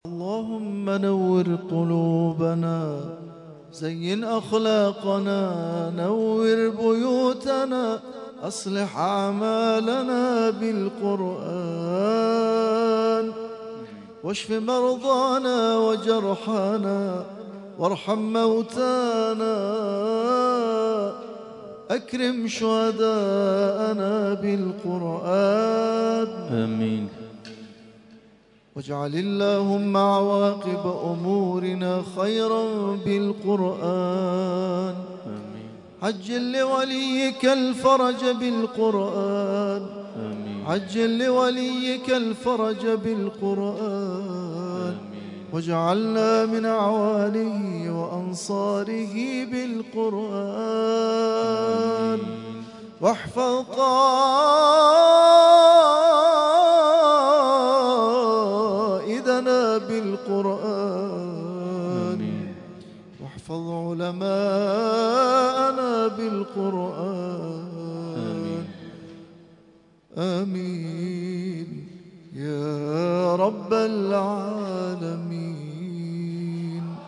ترتیل خوانی جزء ۲۵ قرآن کریم در سال ۱۳۹۲